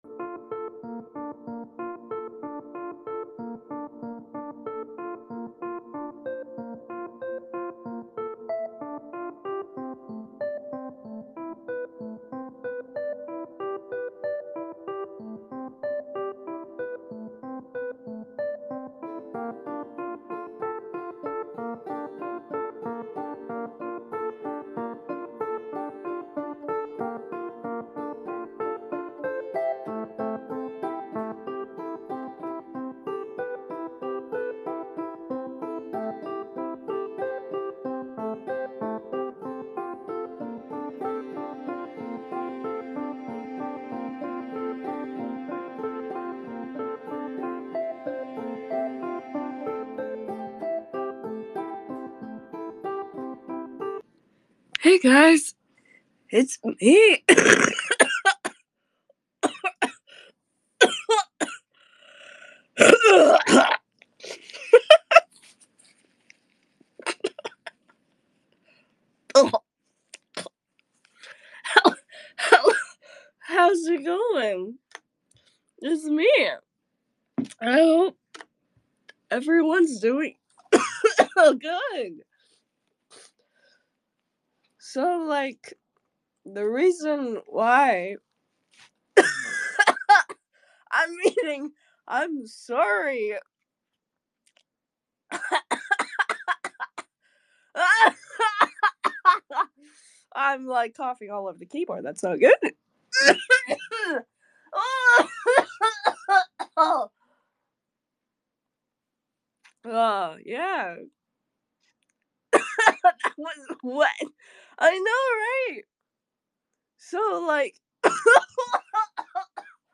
Twitter Space: Hey I’m sick